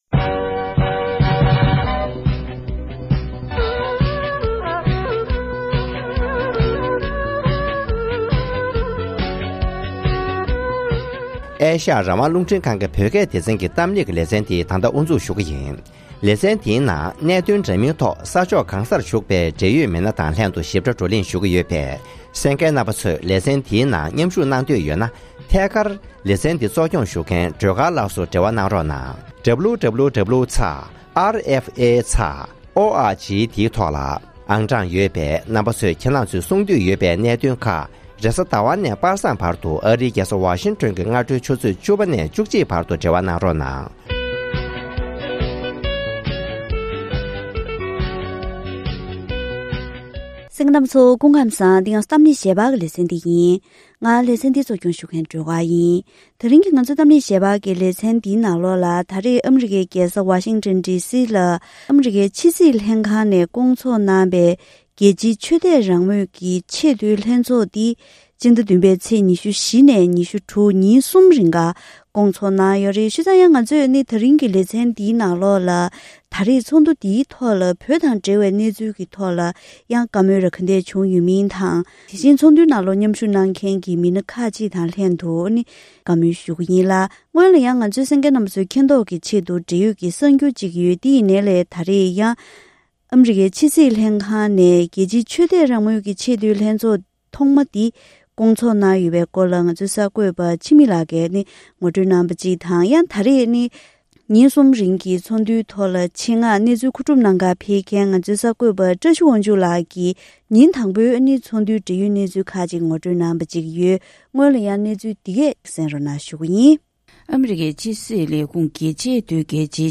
༄༅༎དེ་རིང་གི་གཏམ་གླེང་ཞལ་པར་ལེ་ཚན་ནང་ཨ་རིའི་ཕྱི་སྲིད་ལྷན་ཁང་ནས་རྒྱལ་སྤྱིའི་ཆོས་དད་རང་མོས་ཀྱི་ཆེས་མཐོའི་ལྷན་ཚོགས་ཐེངས་དང་པོ་དེ་ཉིན་གསུམ་རིང་ཨ་རིའི་རྒྱལ་སར་སྐོང་ཚོགས་གནང་ཡོད་པས།